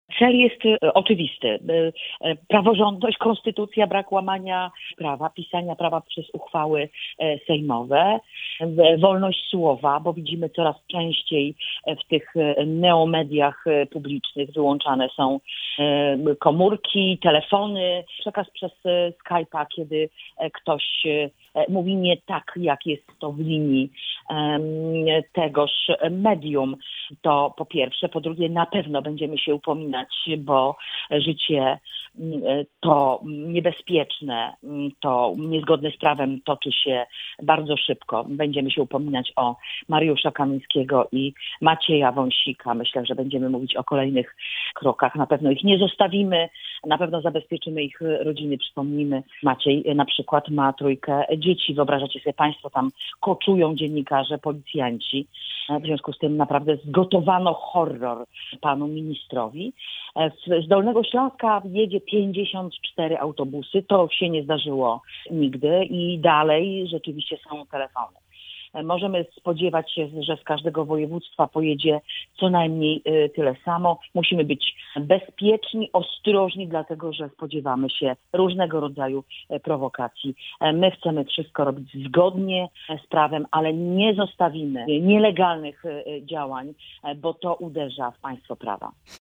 Będziemy upominać o praworządność, wolność słowa, konstytucję, a także o osoby Mariusza Kamińskiego i Macieja Wąsika, mówi europoseł Anna Zalewska.